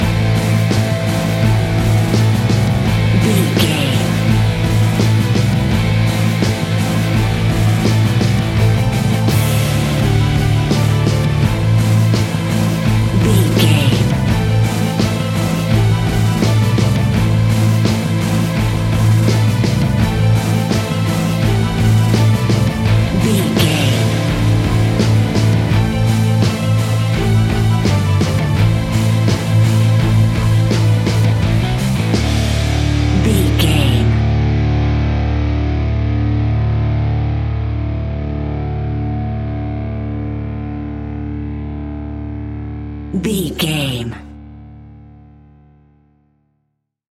Epic / Action
Aeolian/Minor
Slow
metal
hard rock
guitars
scary rock
Heavy Metal Guitars
Metal Drums
Heavy Bass Guitars